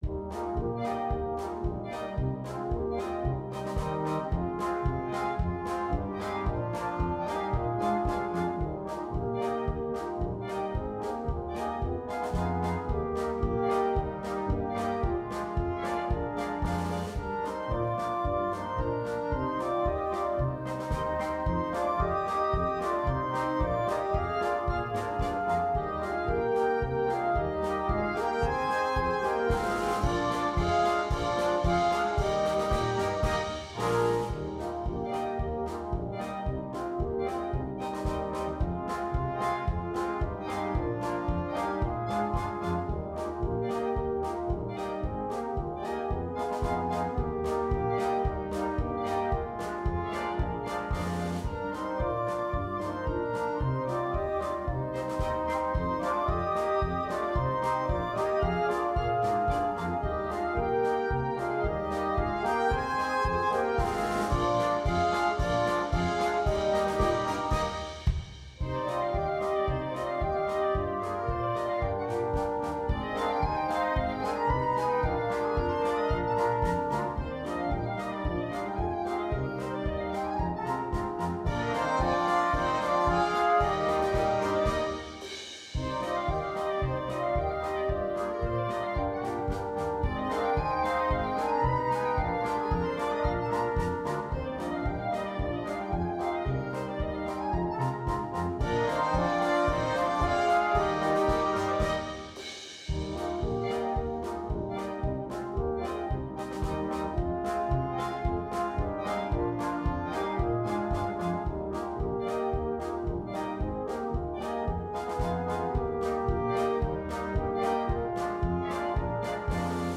2. Blaskapelle
komplette Besetzung
ohne Soloinstrument
Polka